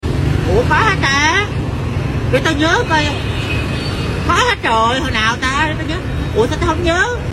ua co ha ta linda Meme Sound Effect